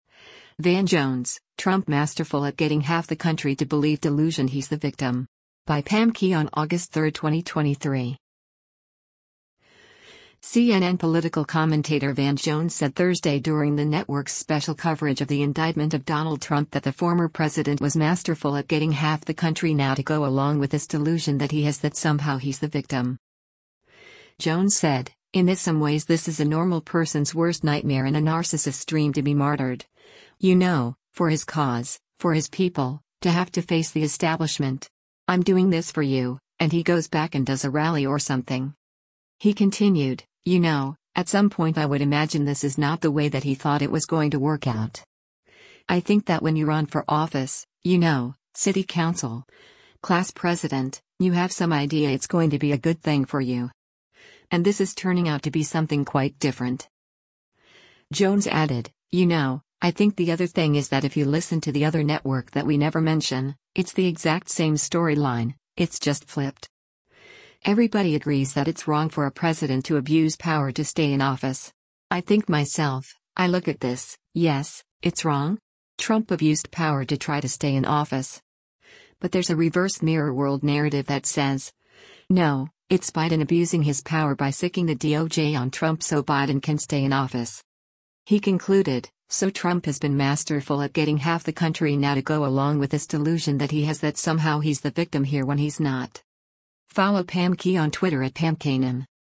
CNN political commentator Van Jones said Thursday during the network’s special coverage of the indictment of Donald Trump that the former president was “masterful at getting half the country now to go along with this delusion that he has that somehow he’s the victim.”